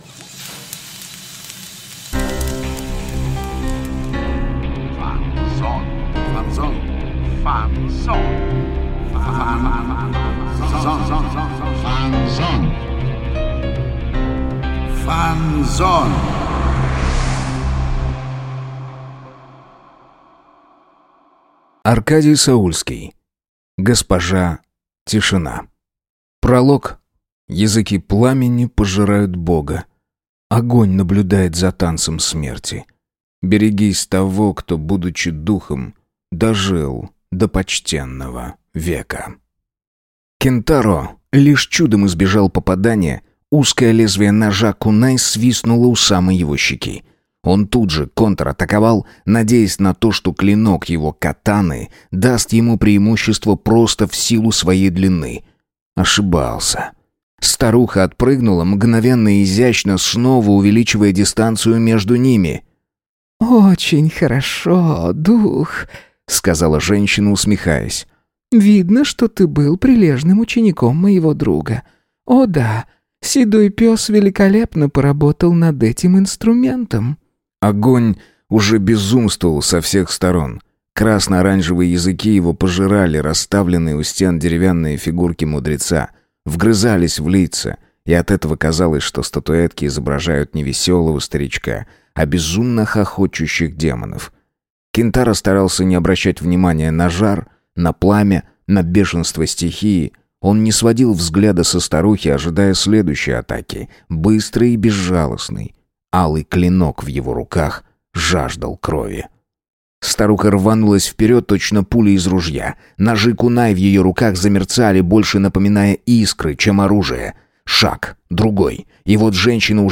Аудиокнига Госпожа Тишина | Библиотека аудиокниг